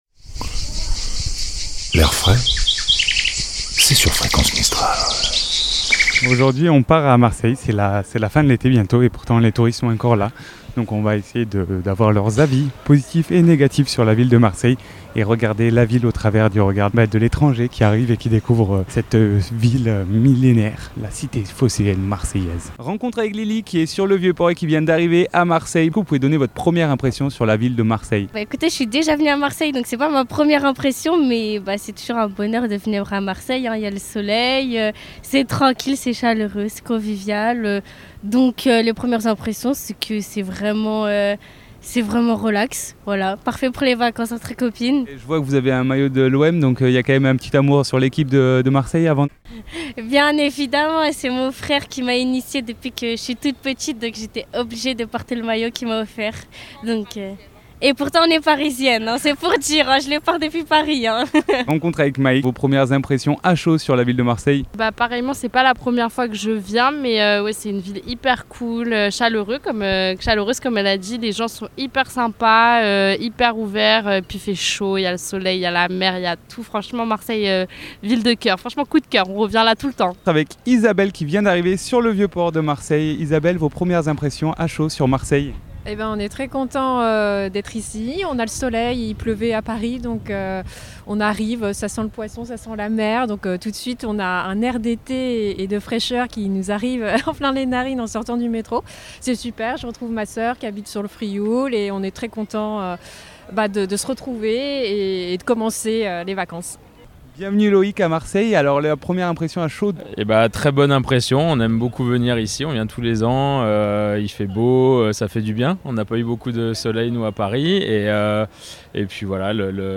Micro trottoir touristes à Marseille.mp3 (4.8 Mo)
Des rencontres et des regards croisés sur la ville, à travers les yeux des touristes et des travailleurs du Vieux-Port, lors d'un micro-trottoir ensoleillé Micro trottoir touristes à Marseille.mp3 (4.8 Mo)